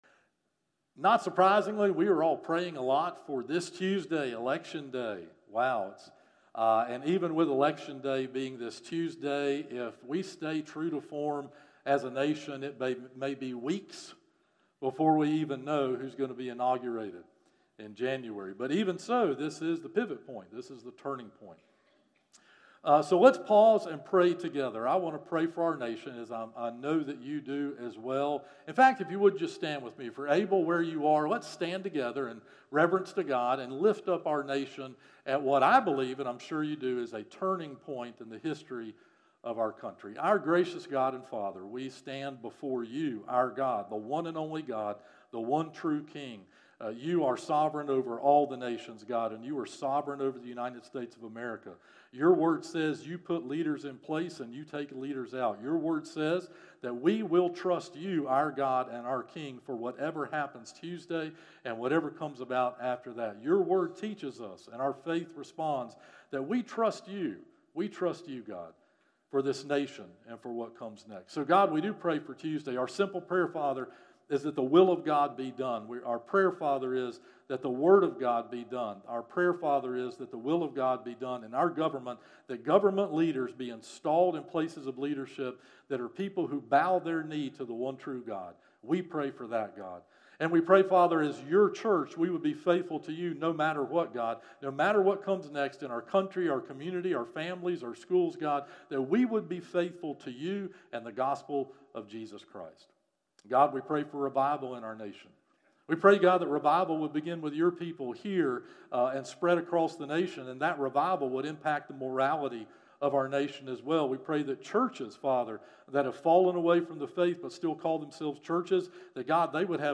Sermons - First Baptist Church of Shallotte
From Series: "Morning Worship - 11am"